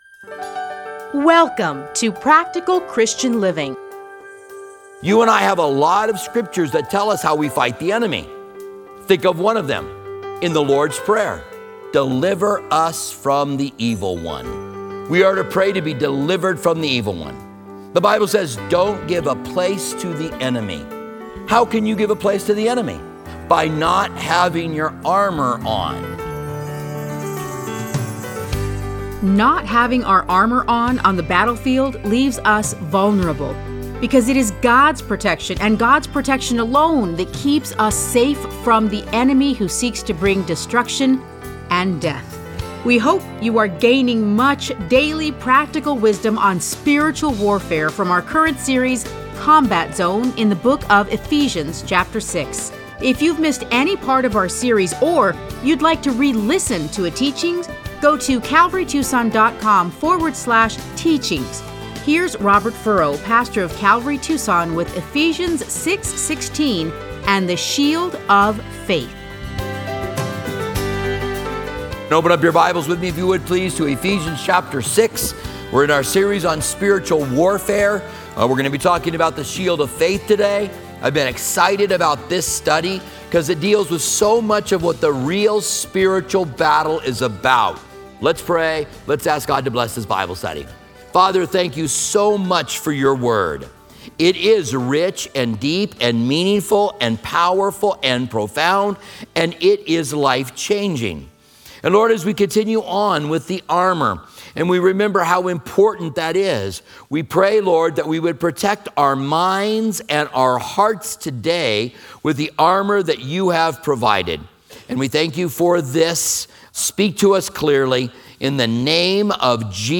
Listen to a teaching from Ephesians 6:16.